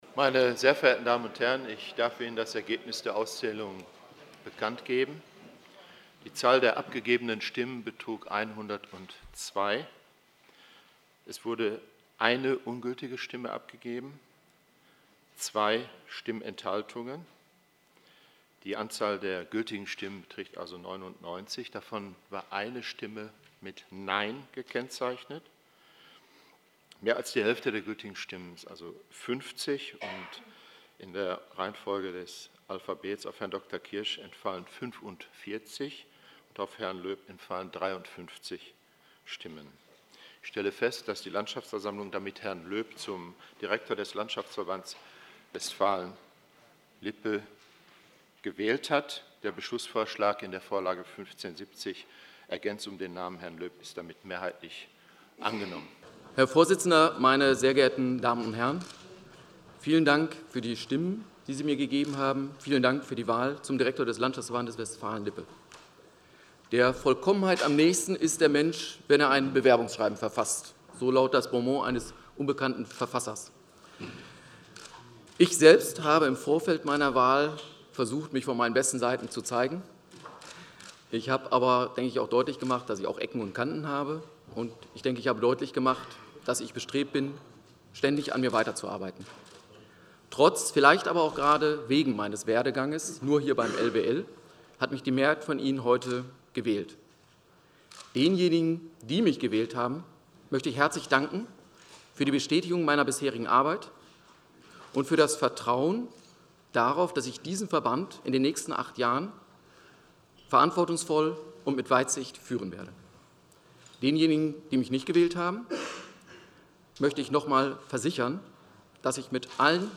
Im Anhang finden Sie den O-Ton vom Wahlergebnis, verkündet von Dieter Gebhard, Vorsitzender der Landschaftsversammlung, und die Ansprache von Matthias Löb.
Anlage 1: O-Ton zur Wahl